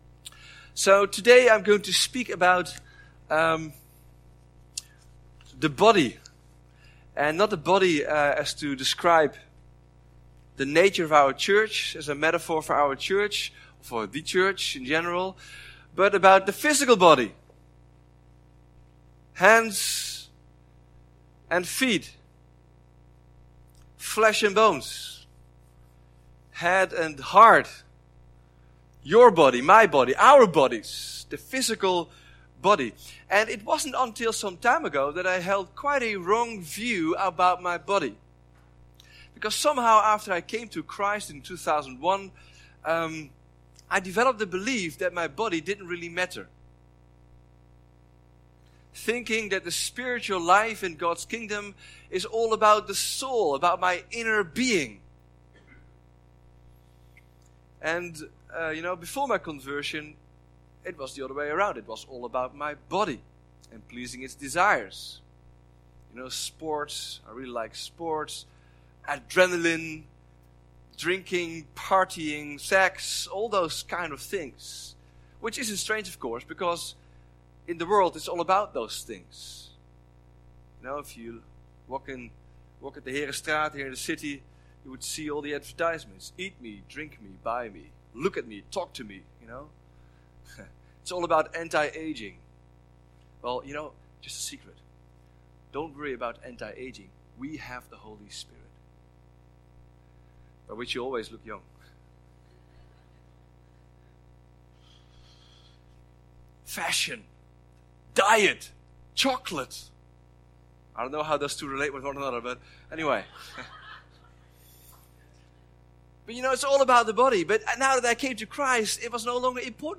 Vineyard Groningen Sermons Going Deeper - #3 Your body matters!